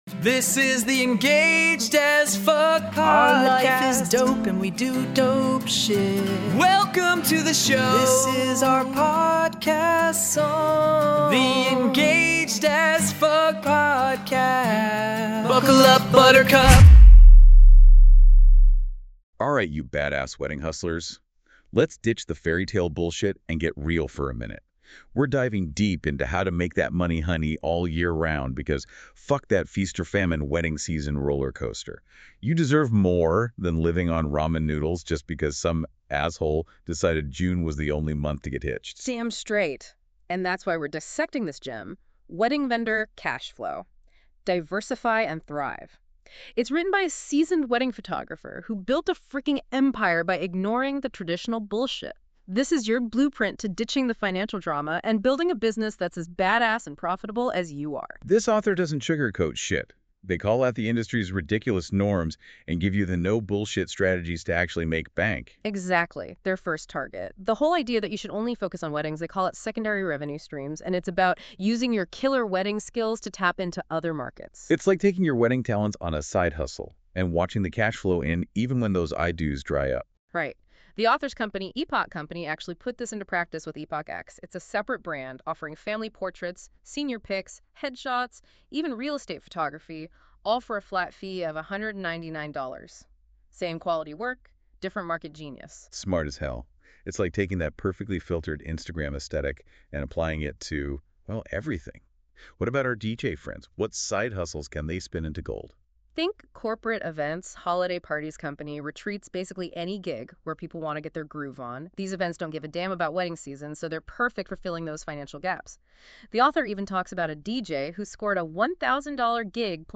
Here’s the secret: We load all that killer magazine content into our AI system, which creates dynamic, unfiltered conversations inspired by the topics we’ve crafted. The result? A podcast that makes our tips, insights, and truths about weddings more digestible for our amazing community.